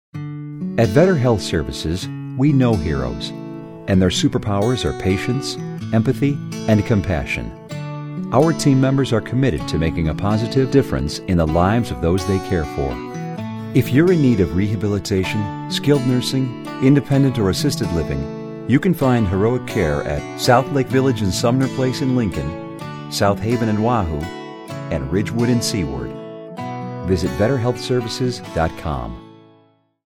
VSL Heroes Radio Spot